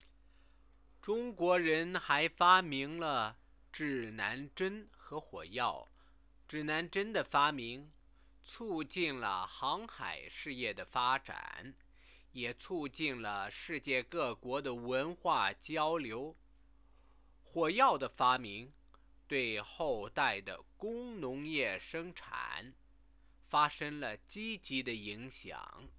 Click "Listen" to hear the sentences spoken Questions (Place the mouse on the questions to view the answers)